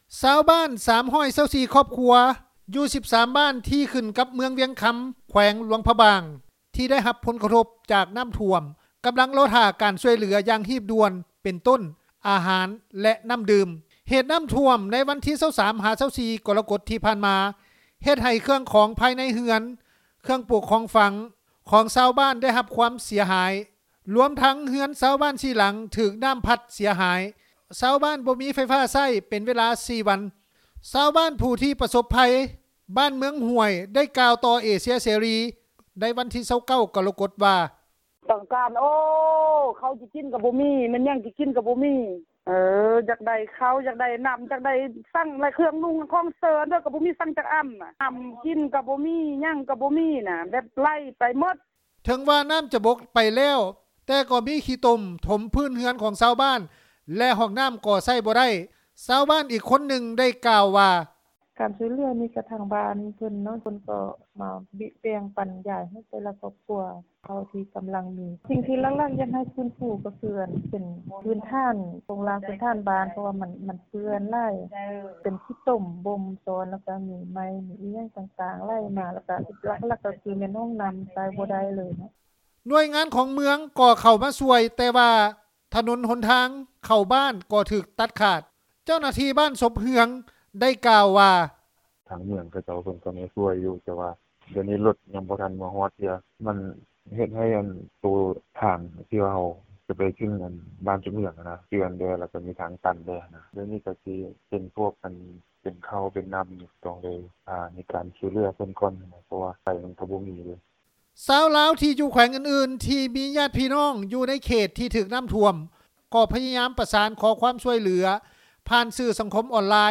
ຊາວບ້ານຜູ້ປະສົບໄພ ບ້ານເມືອງໝ້ວຍ ໄດ້ກ່າວຕໍ່ເອເຊັຽເສຣີ ໃນວັນທີ 29 ກໍລະກົດ ວ່າ:
ຊາວບ້ານອີກຄົນນຶ່ງ ໄດ້ກ່າວວ່າ: